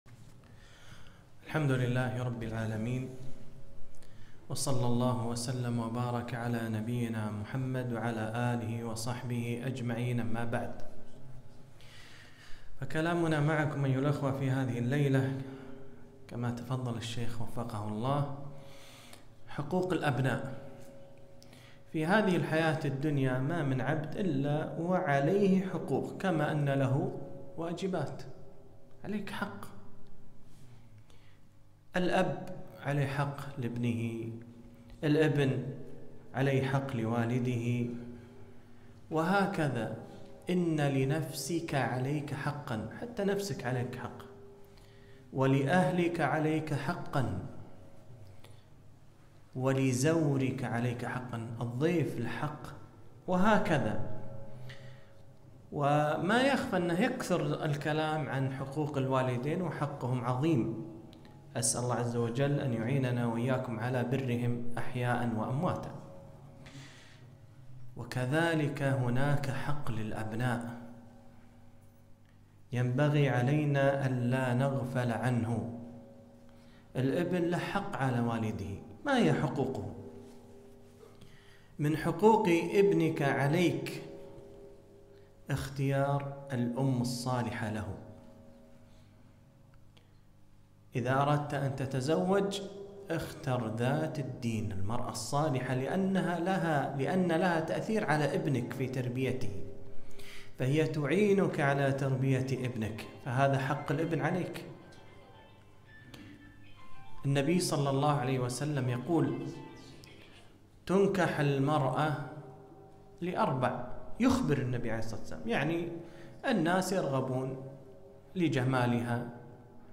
محاضرة - حق الأبناء